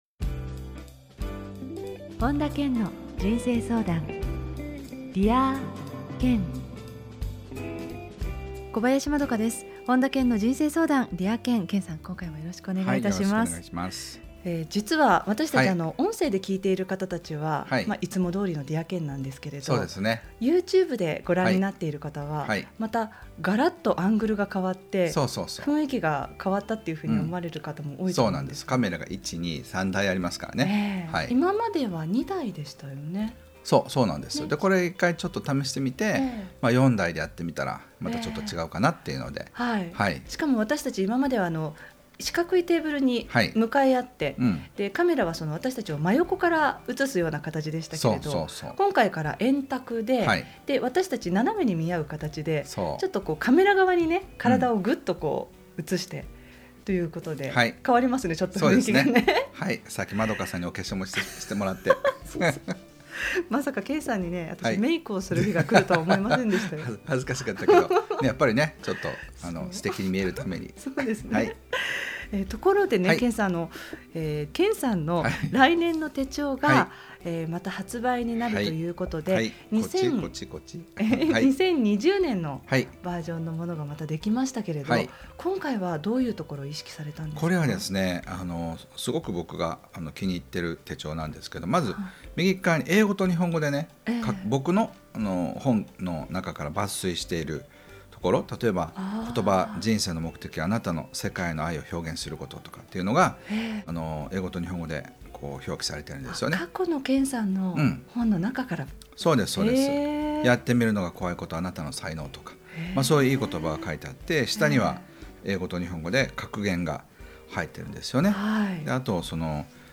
本田健の人生相談 ～Dear Ken～ 傑作選 今回は「夢をどんどん叶えている人の共通点」をテーマに、本田健のラジオミニセミナーをお届けします。